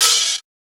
TS OpenHat_6.wav